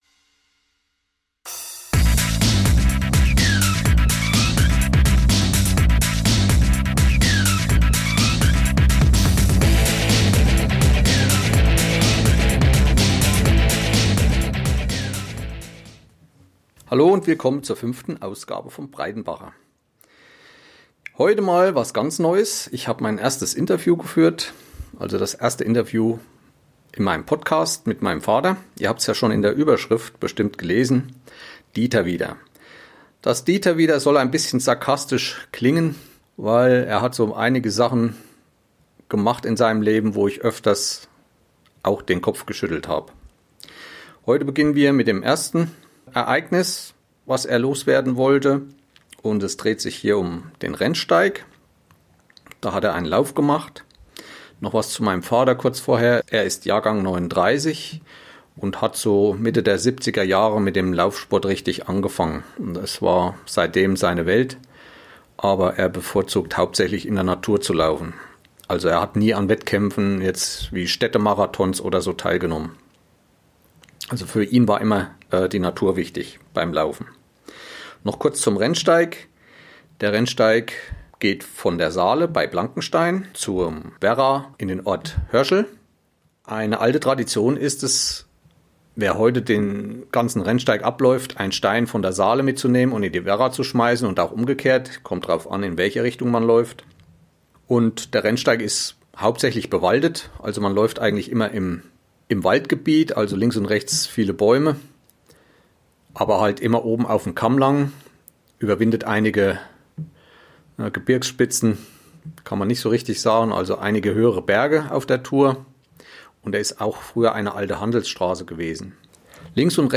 Produziert mit einem ZOOM H1 und Wavelab 8 LE